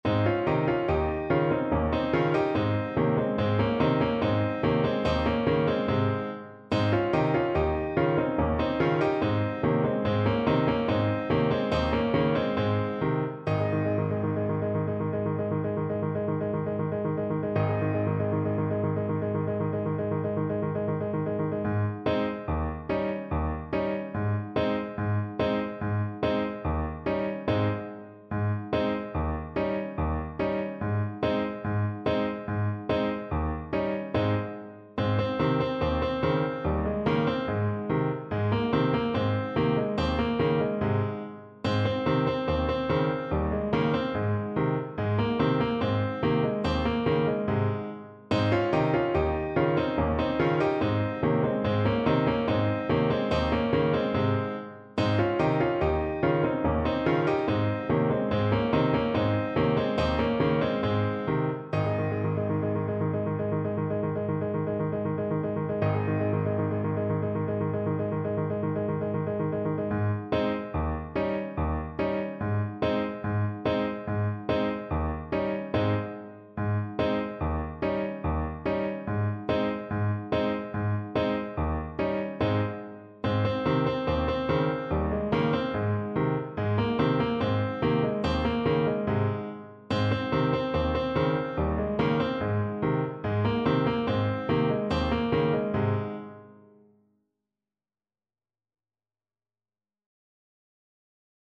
Alto Saxophone
4/4 (View more 4/4 Music)
Cheerfully =c.72